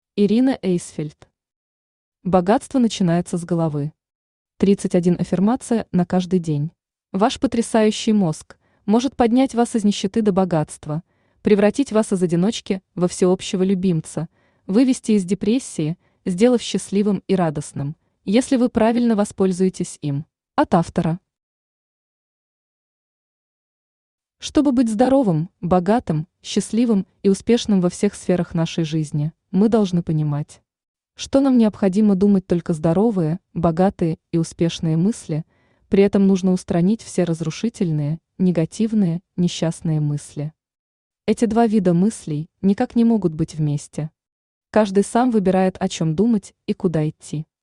Аудиокнига Богатство начинается с головы. 31 аффирмация на каждый день | Библиотека аудиокниг
Aудиокнига Богатство начинается с головы. 31 аффирмация на каждый день Автор Ирина Александровна Эйсфельд Читает аудиокнигу Авточтец ЛитРес.